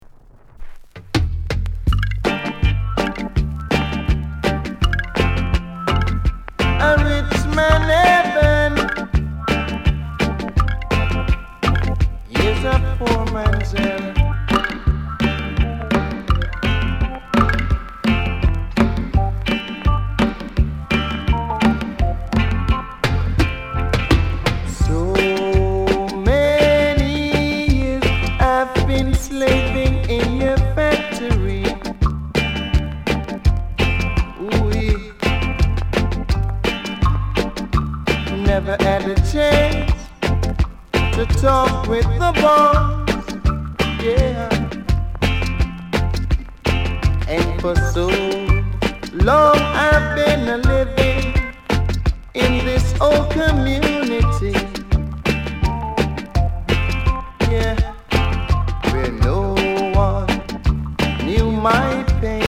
STEPPER ROOTS